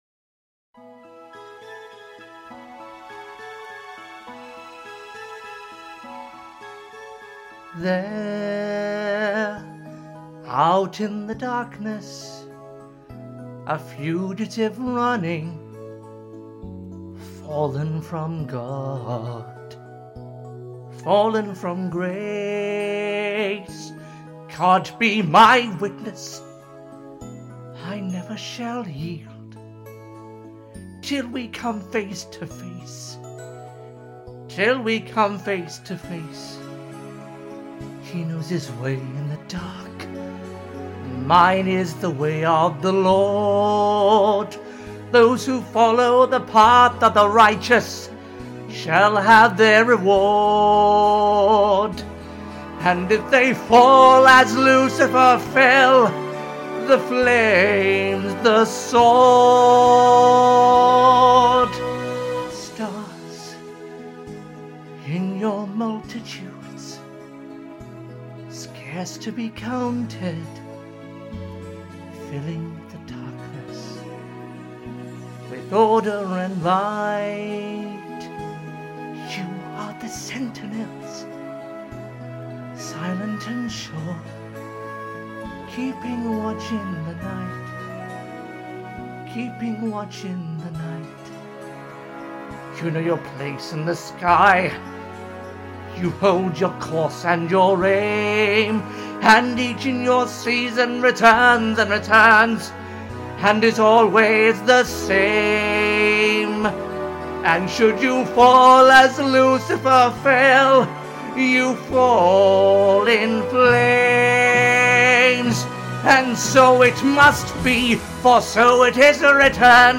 I recorded a cover version